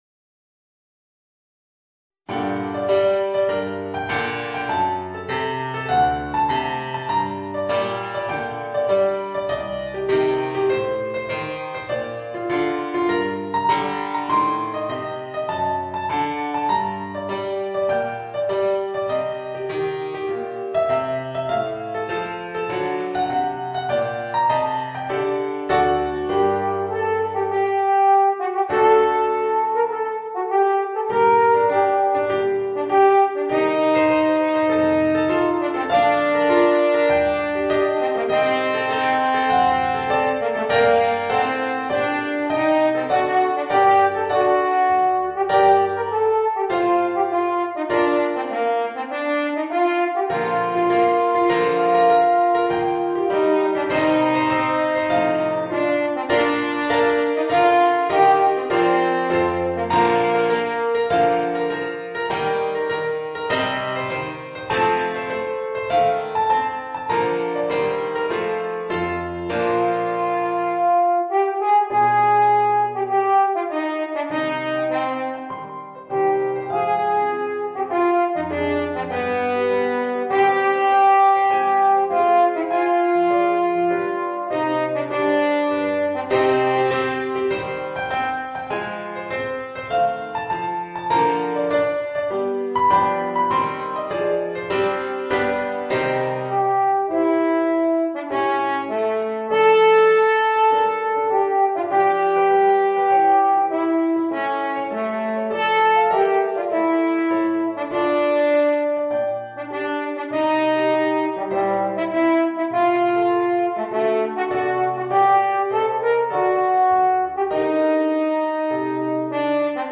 Voicing: Horn and Organ